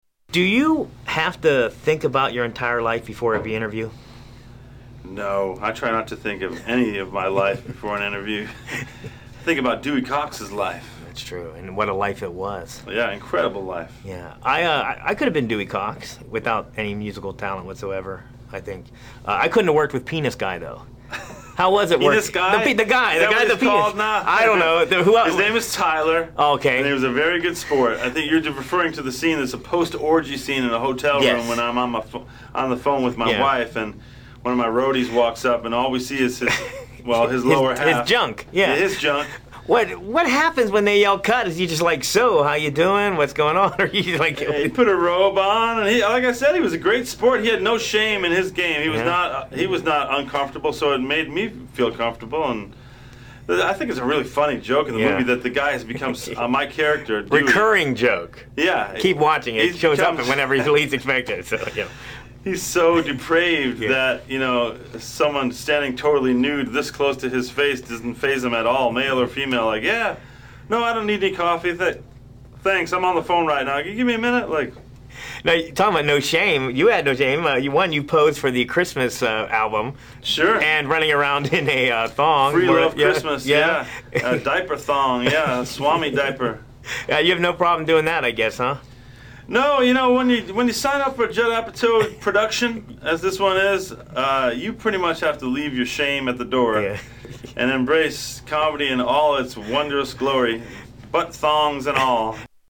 John C. Reilly interview